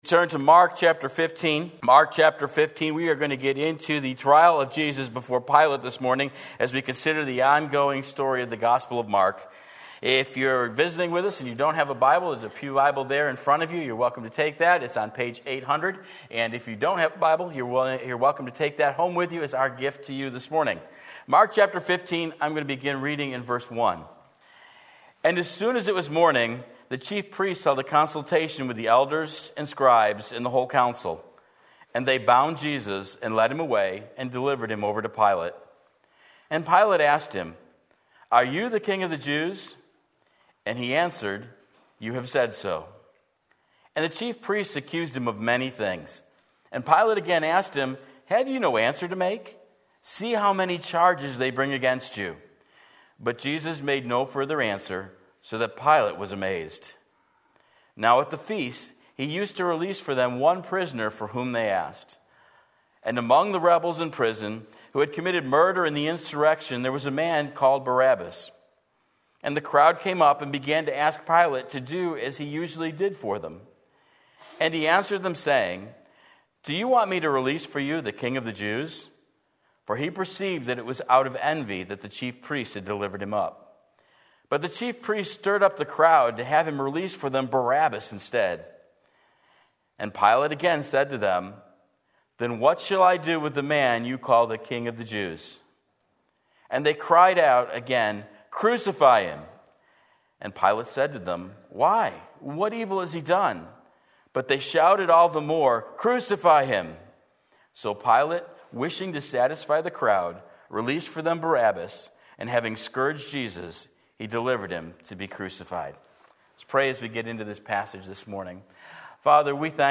Sermon Link
Mark 15:1-15 Sunday Morning Service